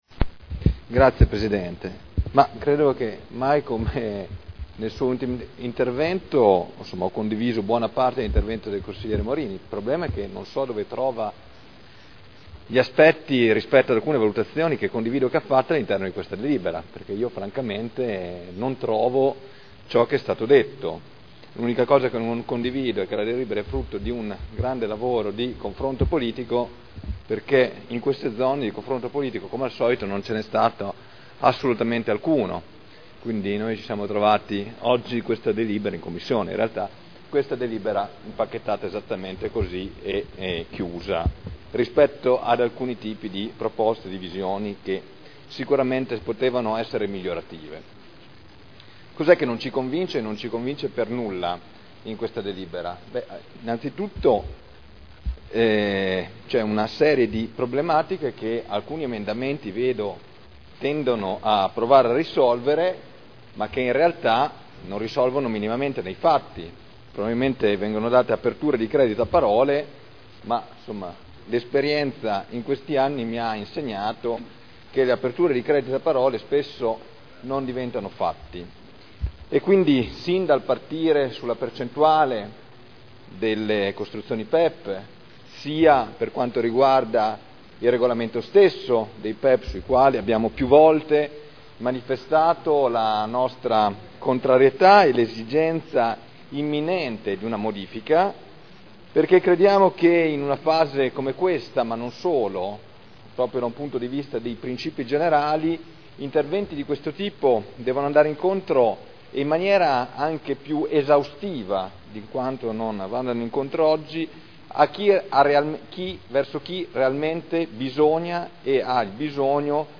Seduta del 19 marzo Proposta di deliberazione Programma per l’edilizia sociale – Principi ed indirizzi – Per un nuovo piano abitativo sociale (Qualità – Sostenibilità – Equità) Dibattito